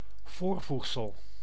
Ääntäminen
IPA: [pʁe.fiks]